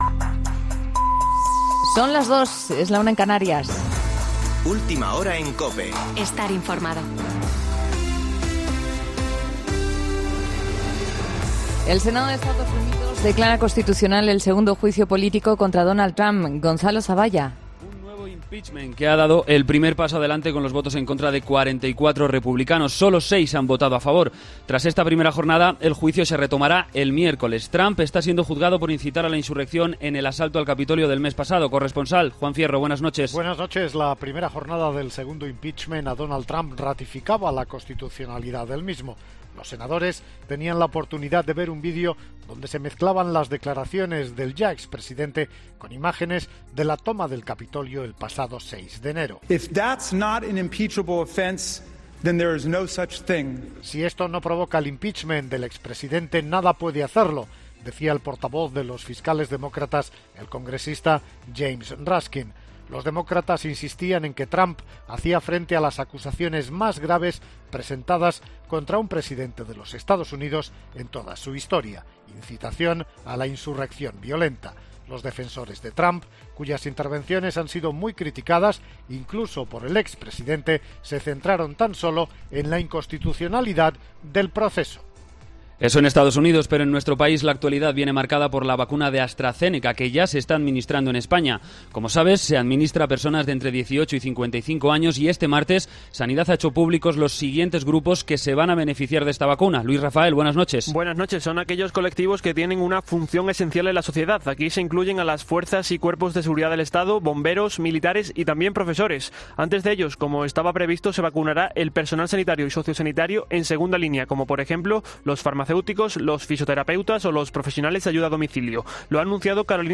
Boletín de noticias COPE del 10 de febrero de 2021 a las 02.00 horas